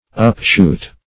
Upshoot \Up*shoot"\